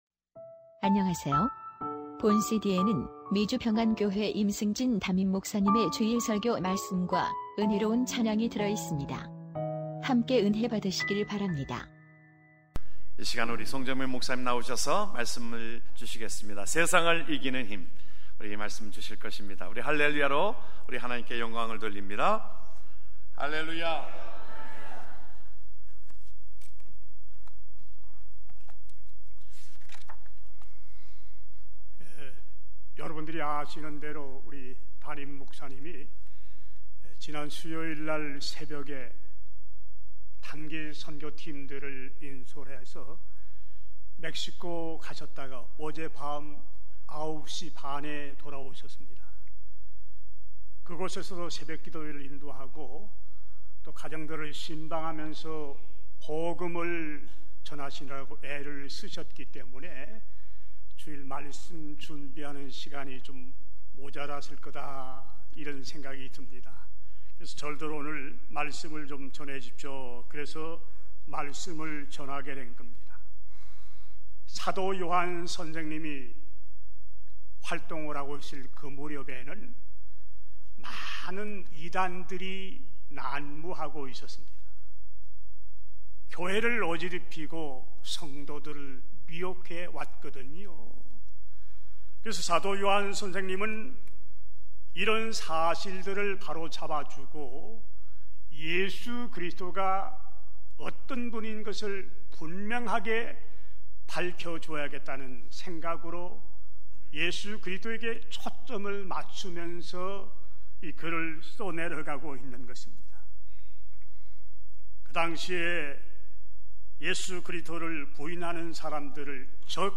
주일설교말씀: 세상을 이기는 힘